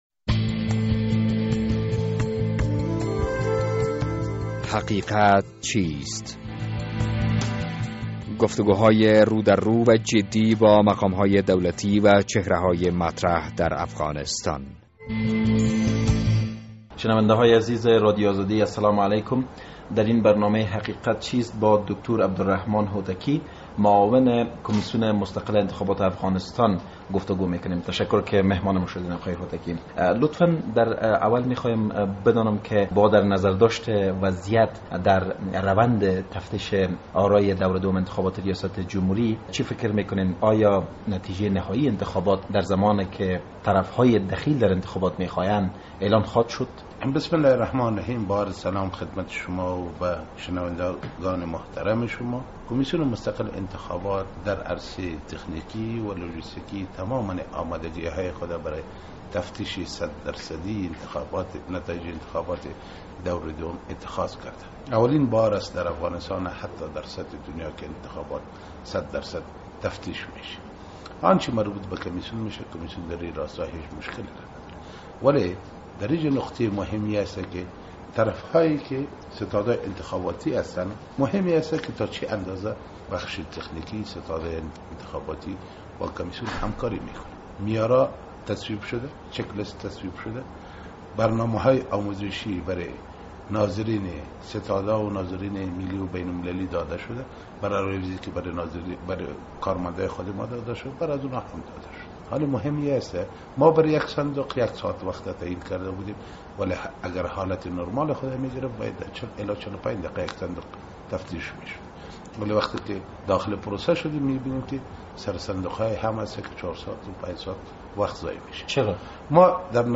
در این برنامه حقیقت چیست با عبدالرحمان هوتکی معاون کمیسیون مستقل انتخابات افغانستان گفتگو کرده ایم.